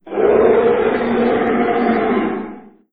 c_trex00_hit1.wav